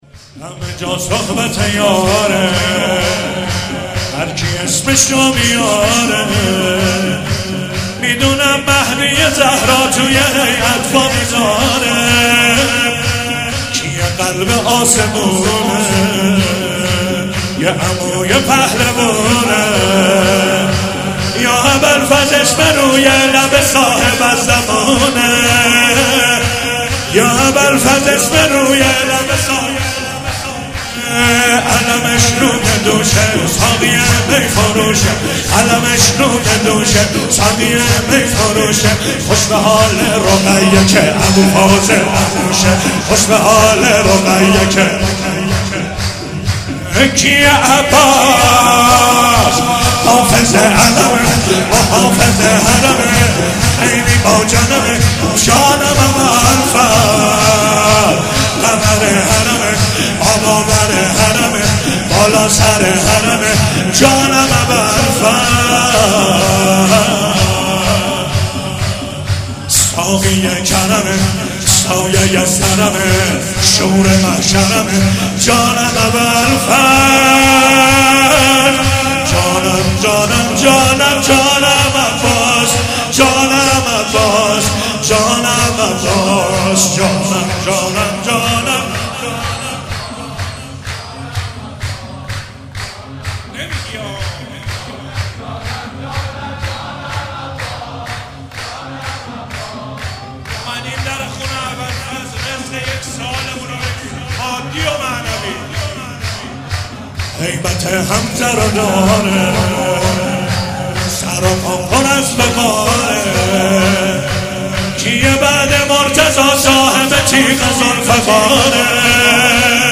ولادت سرداران کربلا98 - شور - همه جا صحبت یار
ولادت سرداران کربلا